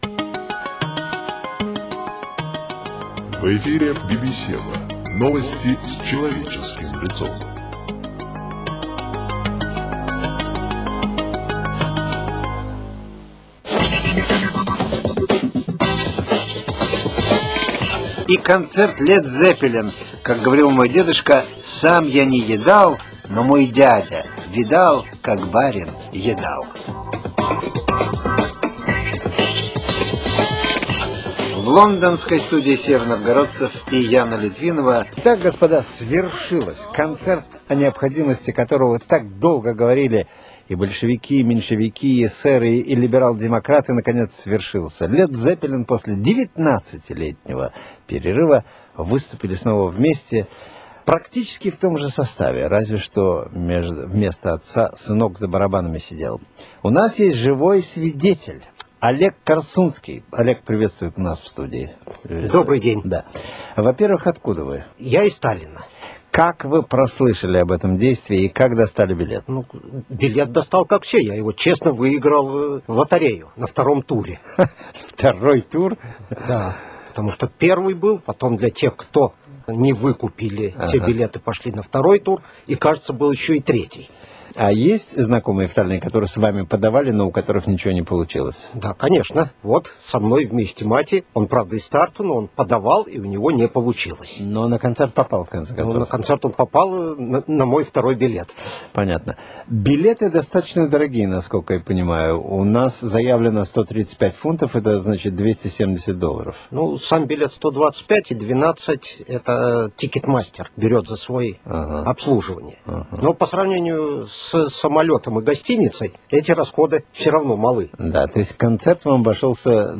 Очень сжатое интервью.